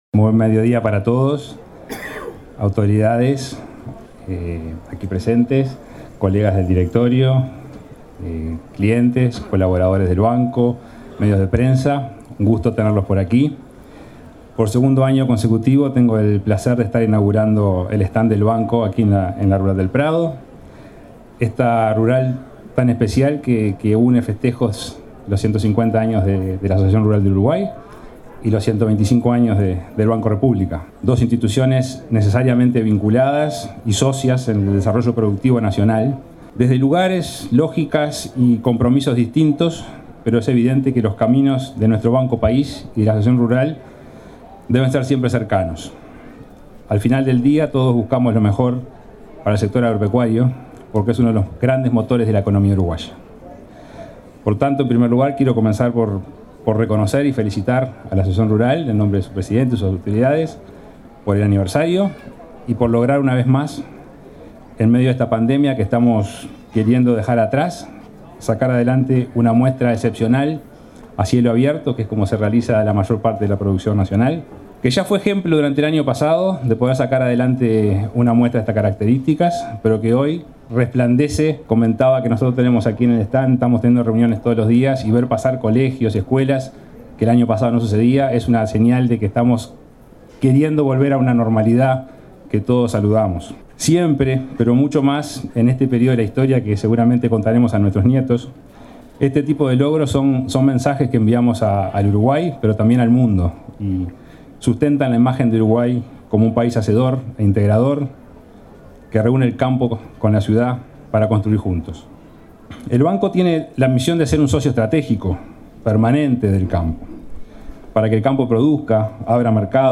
Palabras del presidente del BROU, Salvador Ferrer
Palabras del presidente del BROU, Salvador Ferrer 16/09/2021 Compartir Facebook X Copiar enlace WhatsApp LinkedIn El presidente del Banco República (BROU), Salvador Ferrer, visitó el stand de esa institución en la Expo Prado y destacó el rol que cumple en apoyo al desarrollo del sector productivo.